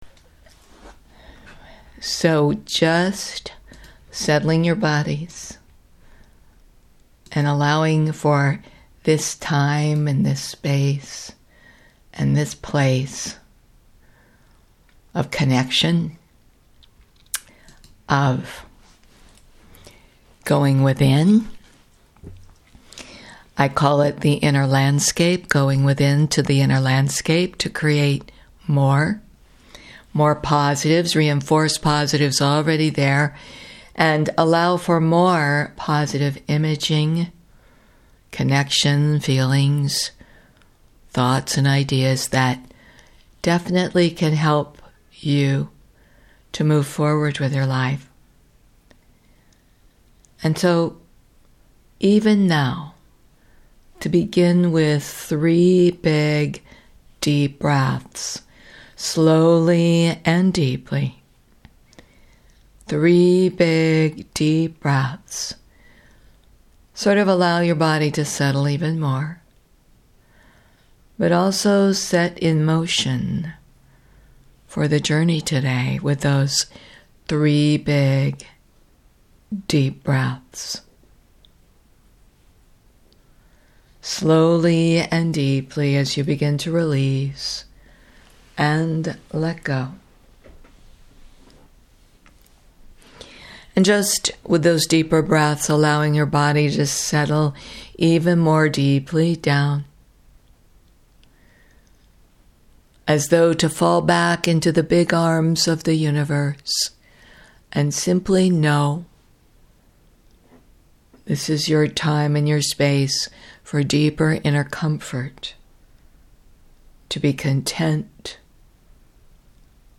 Guided Imagery recorded on June 22